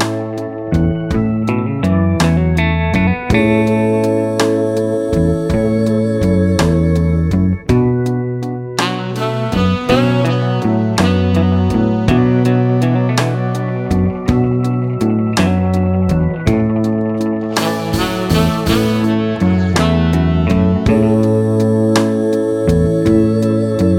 no Backing Vocals Jazz / Swing 2:44 Buy £1.50